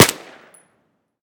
M16_sil-3.ogg